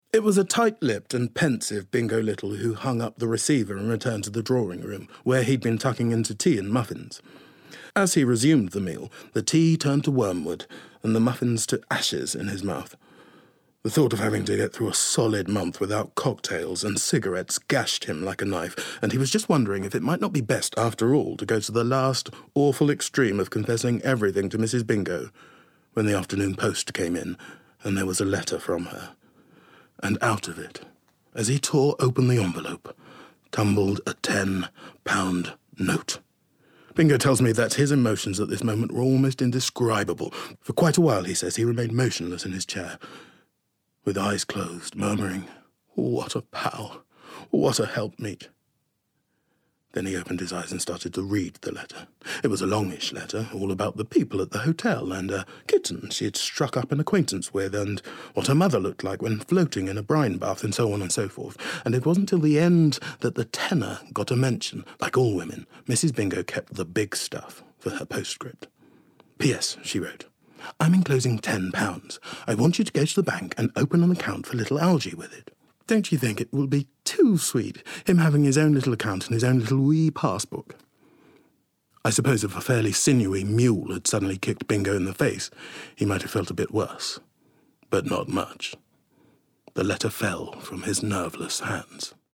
40/50's London/RP Characterful/Relaxed/Versatile